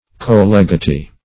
Co-legatee \Co-leg`a*tee"\